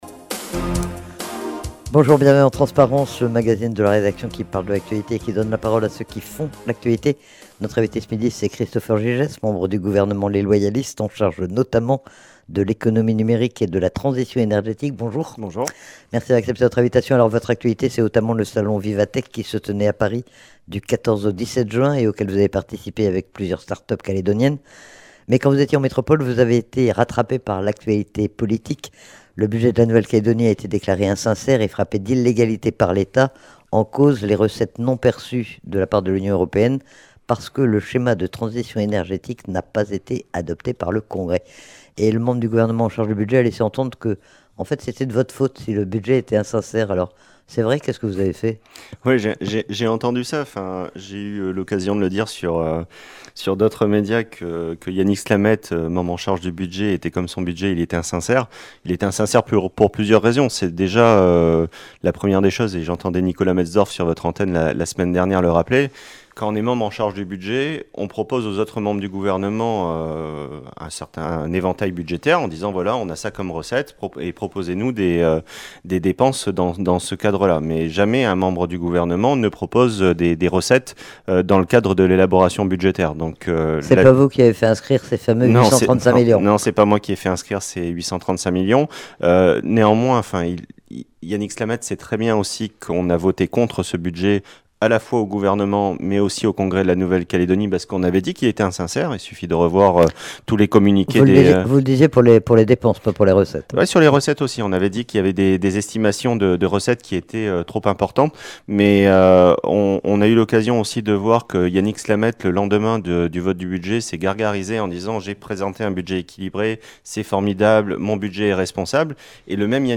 Christopher Gygès, membre du gouvernement en charge, notamment de l'économie numérique est revenu sur le salon "Vivatech" auquel il vient de participer à Paris. Il était également interrogé sur le schéma de transition énergétique et sur l'insincérité du budget de la Nouvelle-Calédonie et, plus largement, sur l'actualité politique calédonienne.